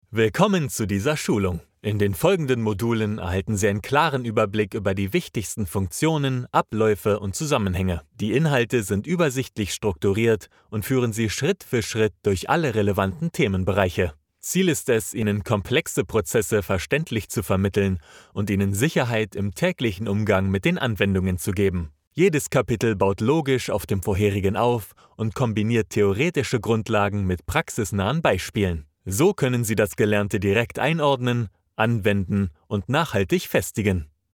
Male
Approachable, Bright, Bubbly, Character
I record from my professionally treated home studio, delivering broadcast-ready audio.
Commercial_Visionary.mp3
Microphone: Neumann TLM 103